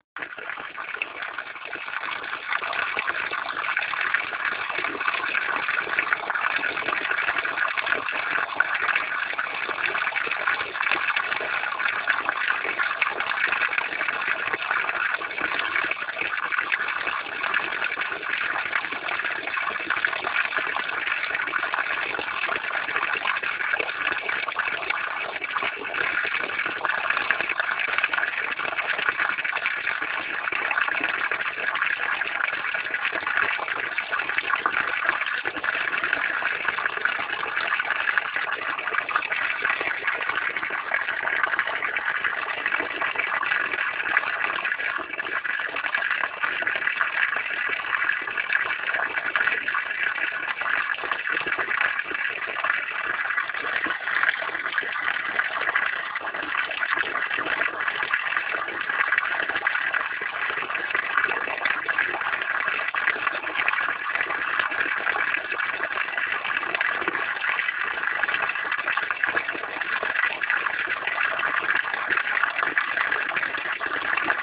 - Portal de Educación de la Junta de Castilla y León - Chorro de agua
Audio de un chorro de agua saliendo de una pared de un huerto.